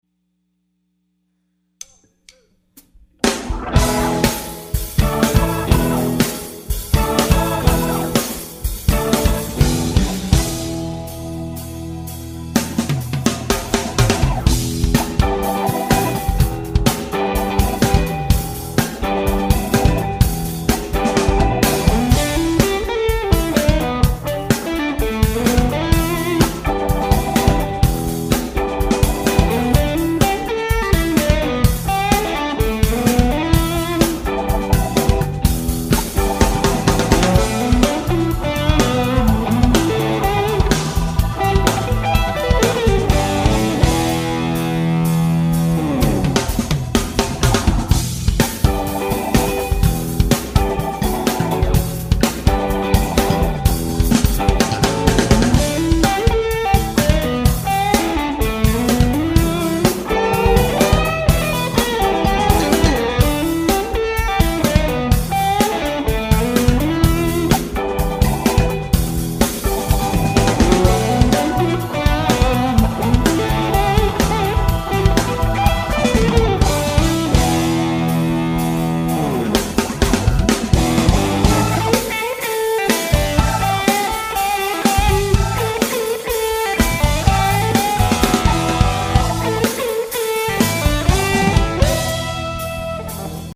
- as I find that horn attack envelope harder to achieve than a sustaining Brit type rock sound.
Great playing too.